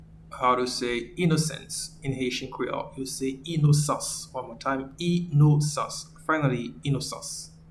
Pronunciation:
Innocence-in-Haitian-Creole-Inosans.mp3